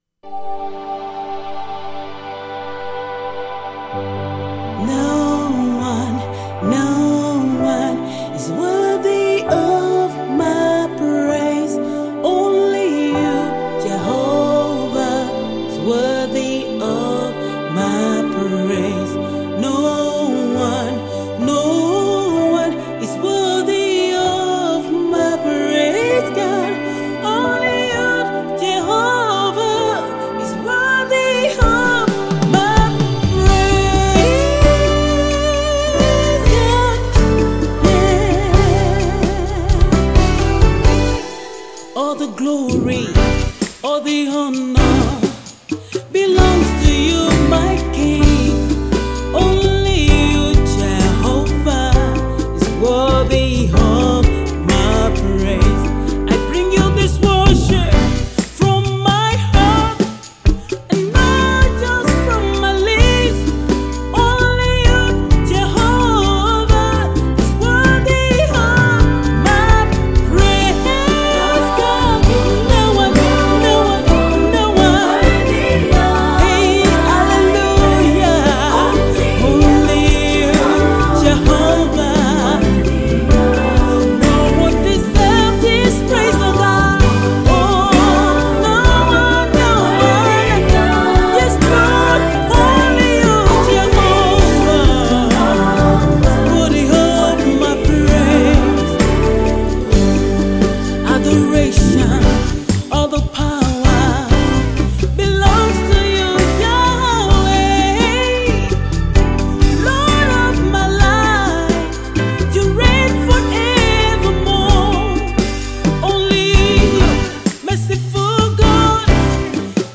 Australia-based Nigerian gospel artiste
praise-worship